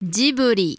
The name was chosen by Miyazaki due to his passion for aircraft and for the idea that the studio would "blow a new wind through the anime industry".[11][12] Although the Italian word would be more accurately transliterated as "Giburi" (ギブリ), with a hard g sound, the studio's name is written in Japanese as Jiburi (ジブリ, [dʑiꜜbɯɾi]
Ja-Ghibli.oga.mp3